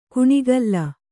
♪ kuṇigalla